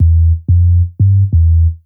bass_04.wav